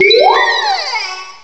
cry_not_lampent.aif